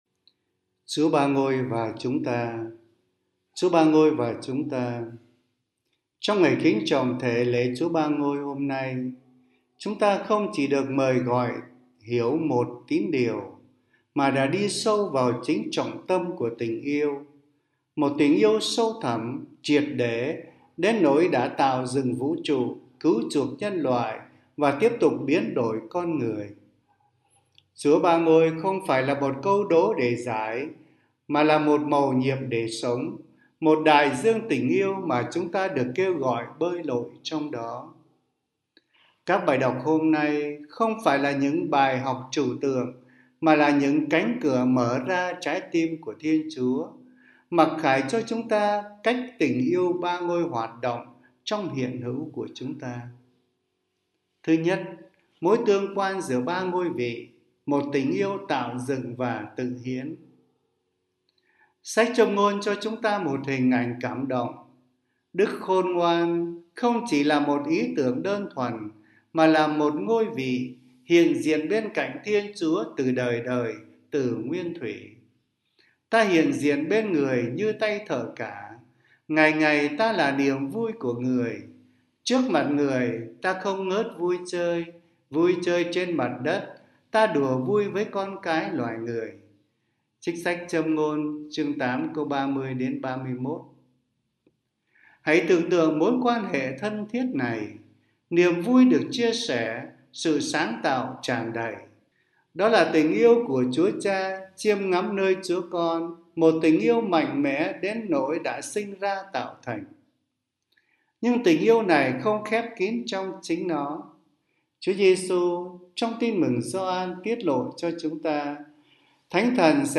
Suy niệm Chúa Nhật
Nữ miền Nam